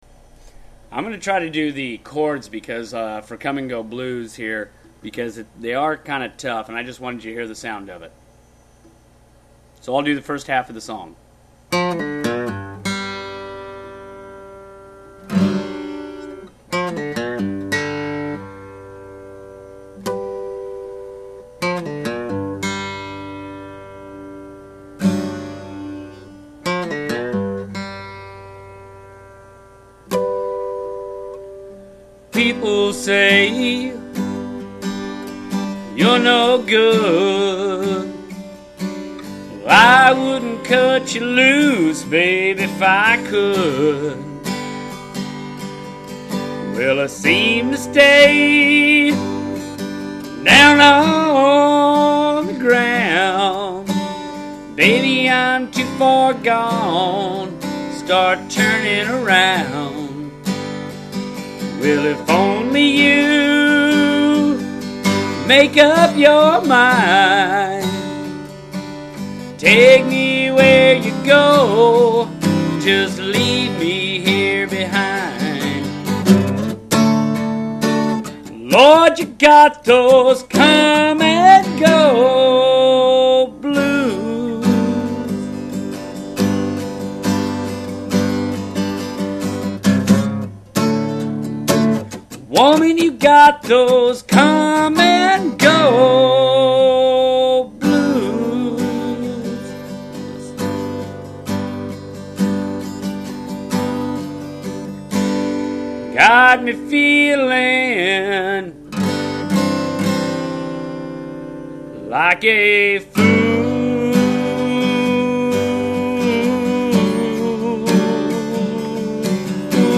Open G  (DGDGBD)